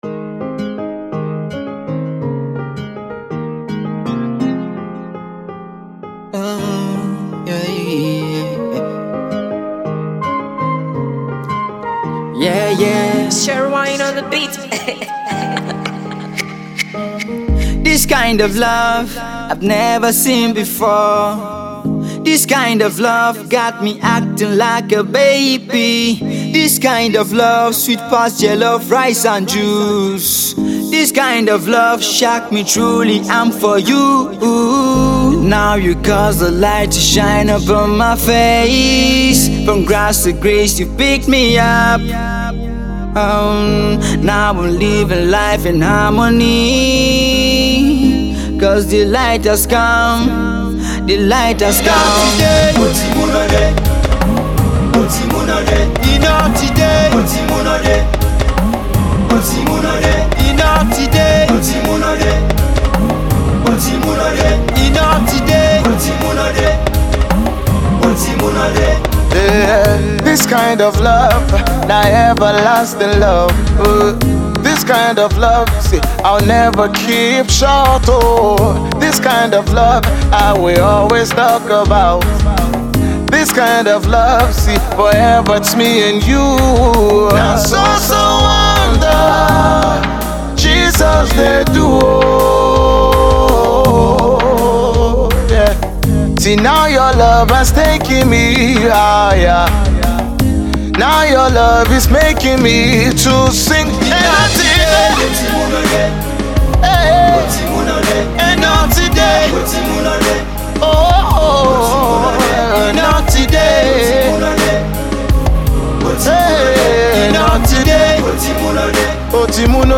Nigerian gospel song